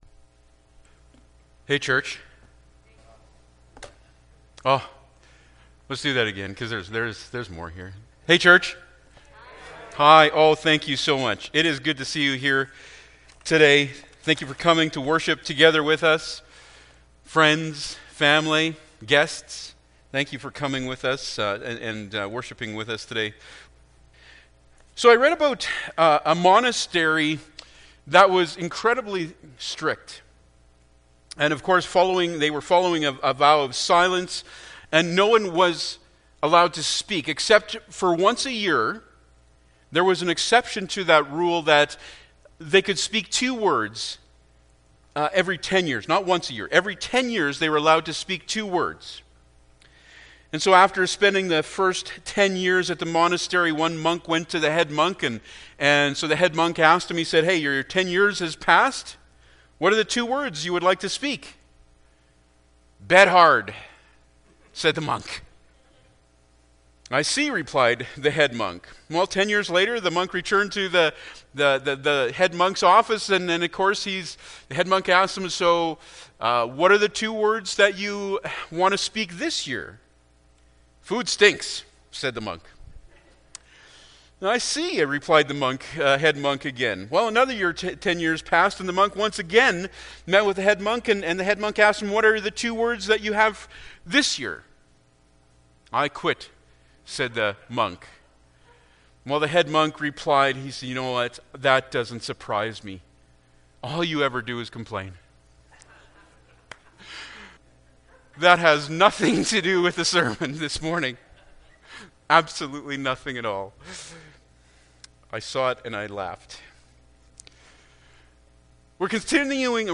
Ephesians 6:10-18 Service Type: Sunday Morning Bible Text